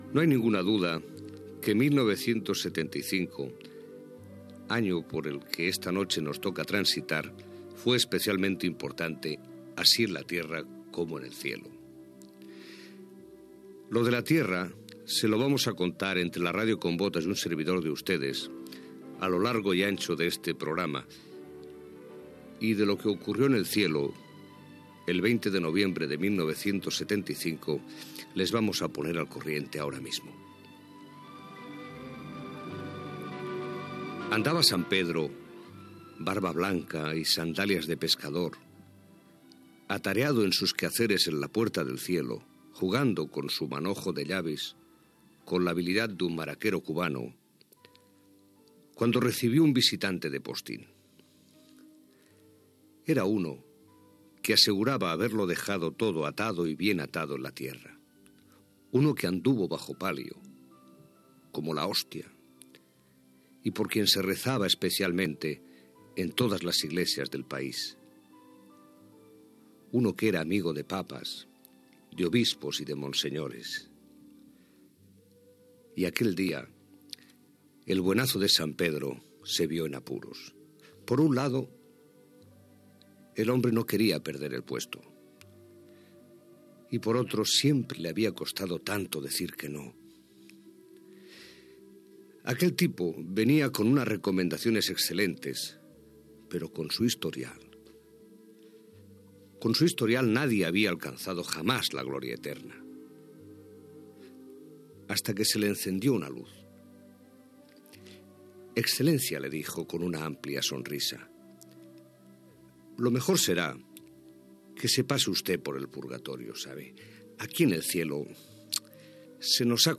Extret del programa "El sonido de la historia", emès per Radio 5 Todo Noticias el 24 de novembre de 2012.